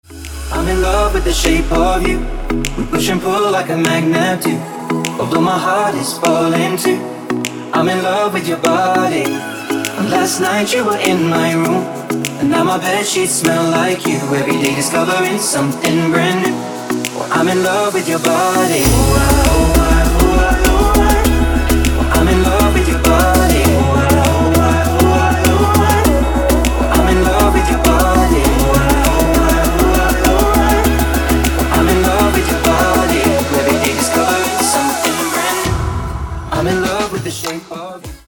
• Качество: 224, Stereo
поп
мужской вокал
dance
vocal